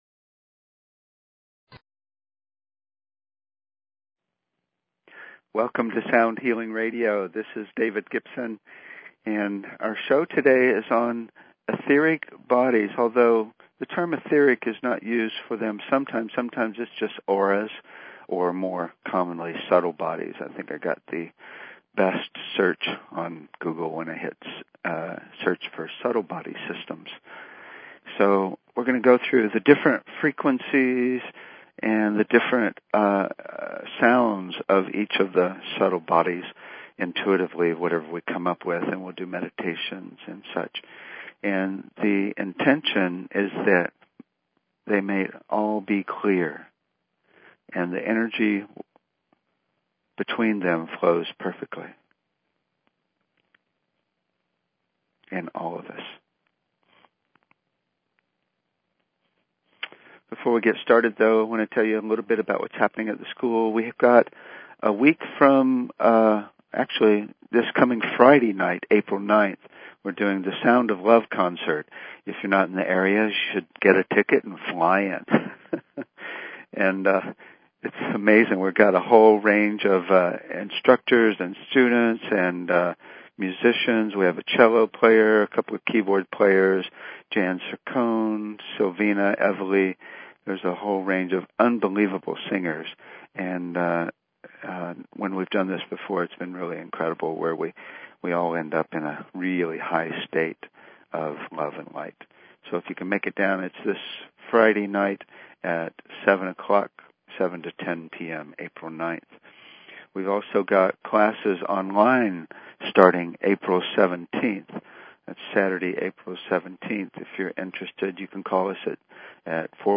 Talk Show Episode, Audio Podcast, Sound_Healing and Courtesy of BBS Radio on , show guests , about , categorized as
THE SOUND OF AURAS OR SUBTLE BODIES We will explore the Auras and the frequencies associated with them. We will do meditations to help heal and harmonize them.